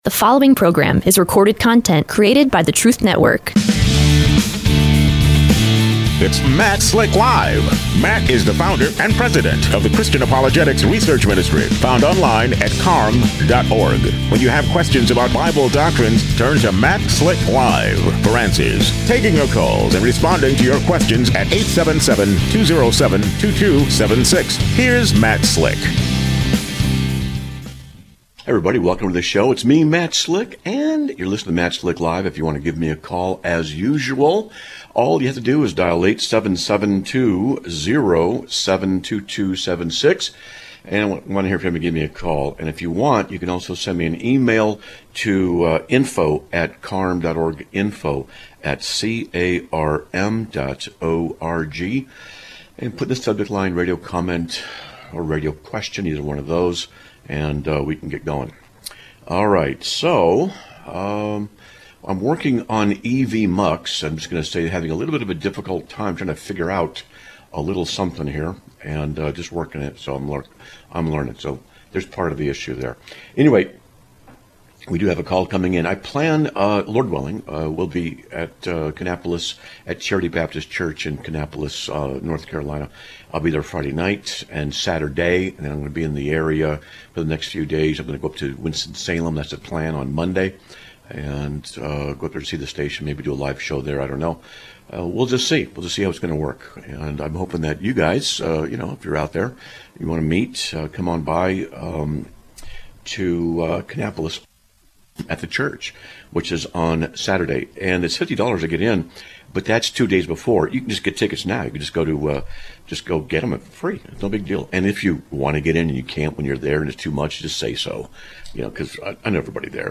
Live Broadcast of 08/12/2025